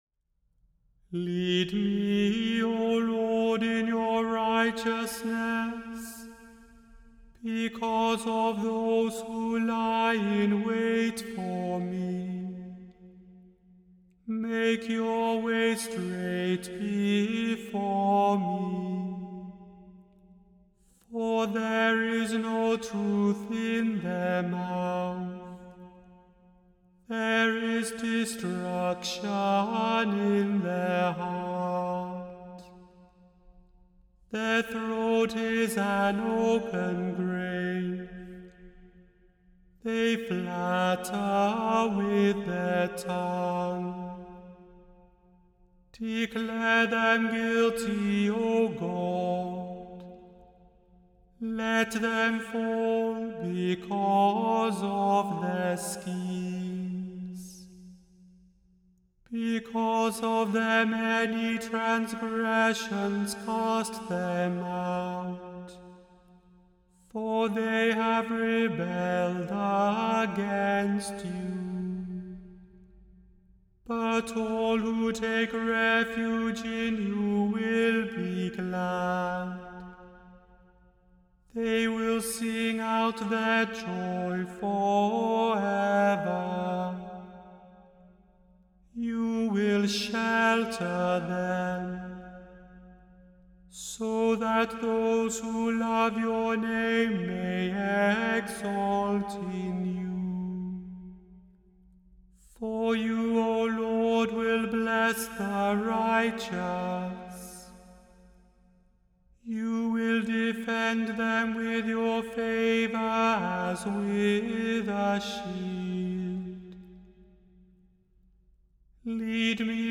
The Chant Project – Chant for Today (September 27) – Psalm 5 vs 8-15